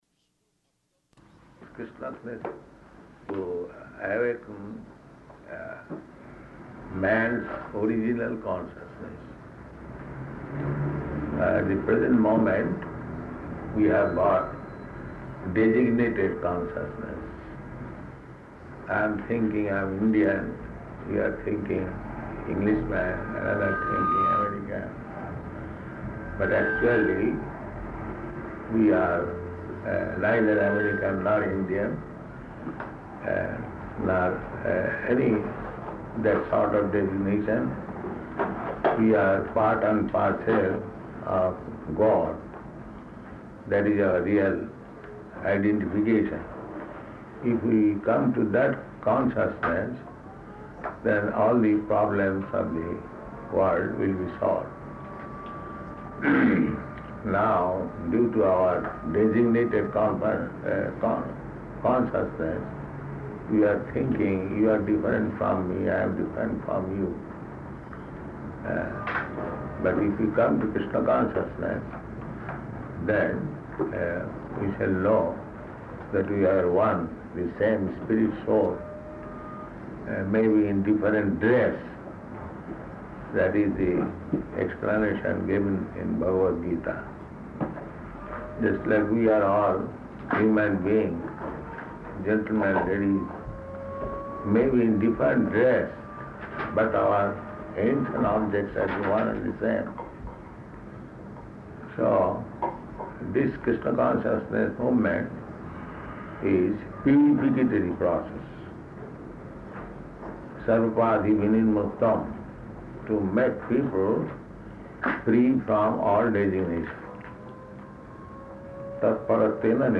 Temple Press Conference
Temple Press Conference --:-- --:-- Type: Conversation Dated: August 5th 1971 Location: London Audio file: 710805PC-LONDON.mp3 Prabhupāda: Kṛṣṇa's message to awaken man's original consciousness.